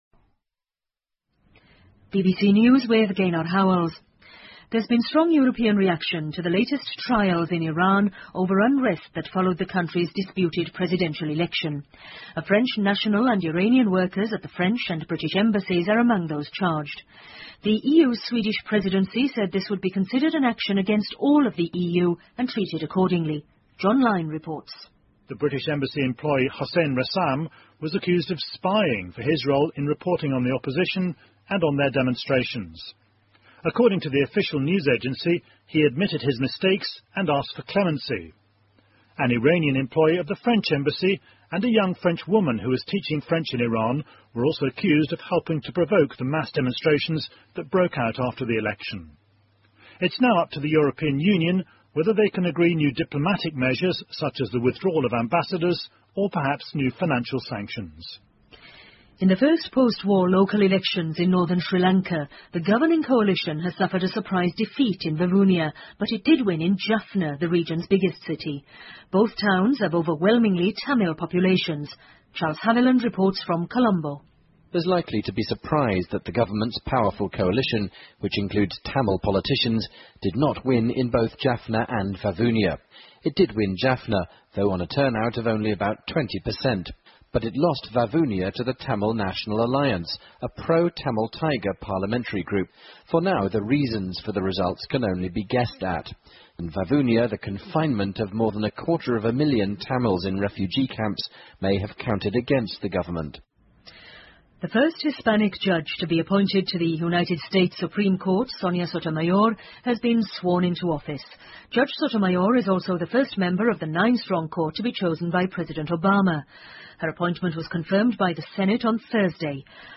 英国新闻听力 IMF要求各国采取进一步行动稳定全球金融体系 听力文件下载—在线英语听力室